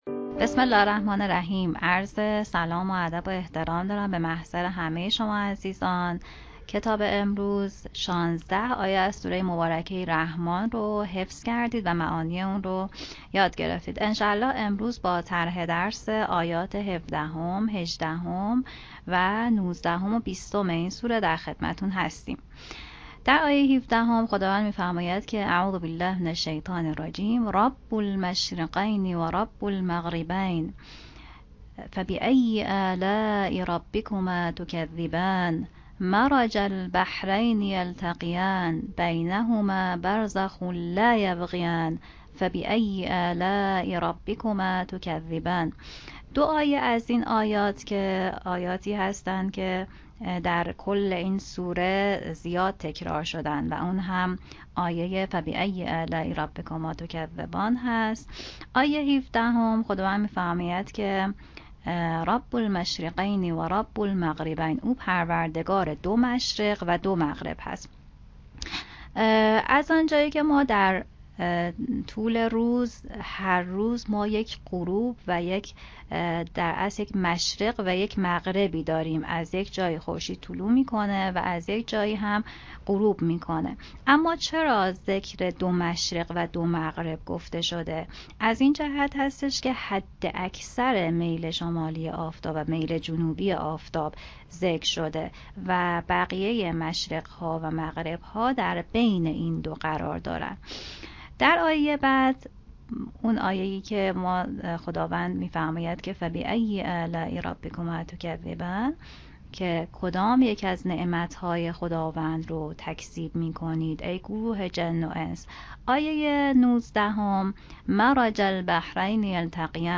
عموم علاقه‌مندان در تمامی گروه‌های سنی می‌توانند با مشاهده فیلم آموزشی (حدود ۷ دقیقه) با تدریس دو نفر از اساتید قرآنی، که هر روز در ایکنا منتشر می‌شود، در کنار فراگیری مفاهیم، نکات تدبری و حفظ سوره الرحمن، در مسابقه روزانه پیامکی نیز شرکت و جوایز نقدی را شامل سه کارت هدیه پنج میلیون ریالی به قید قرعه (سه نفر از شرکت‌کنندگانی که پاسخ صحیح دو پرسش سه‌گزینه‌ای را داده‌اند) دریافت کنند.
آموزش حفظ سوره الرحمن